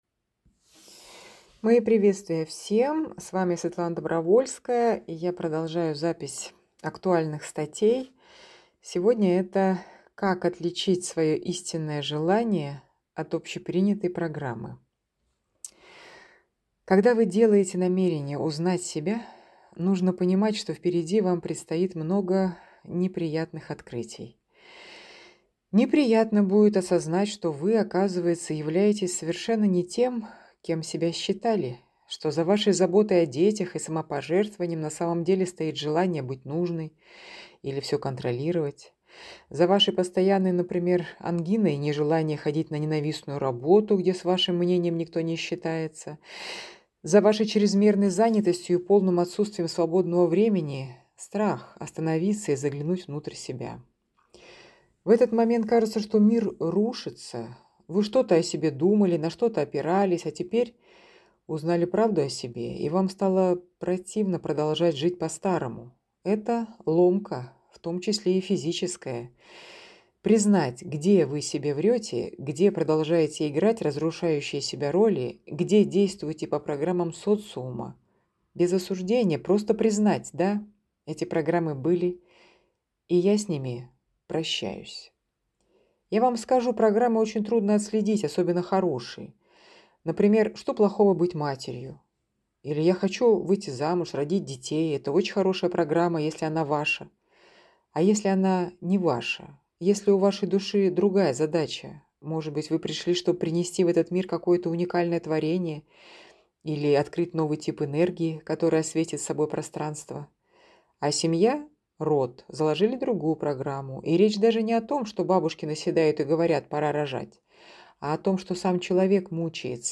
Аудио вариант статьи в исполнении автора: